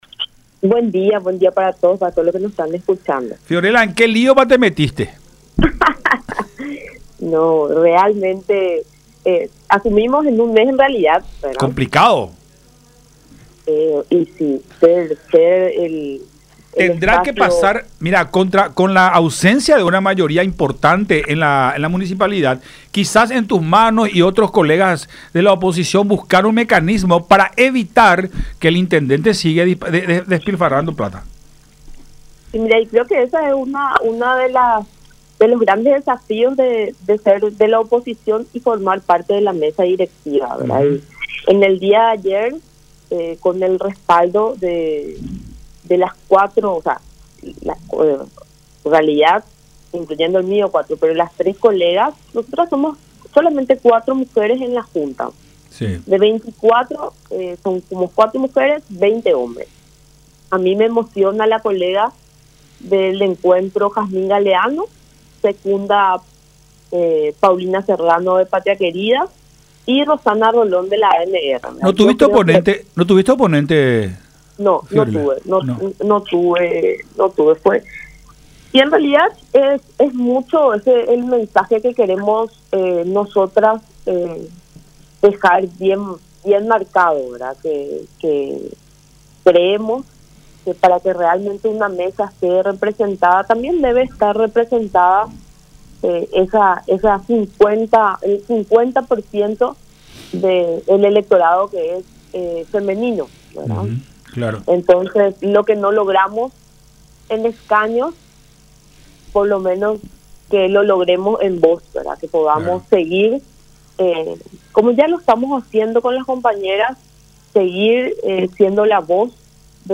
Lo que nosotras vamos a hacer es reactivar y potenciar ese albergue”, afirmó en conversación con Nuestra Mañana a través de Unión TV y radio La Unión.